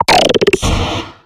Audio / SE / Cries / CLAWITZER.ogg
CLAWITZER.ogg